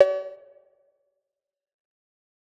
Destroy - Perc Cow.wav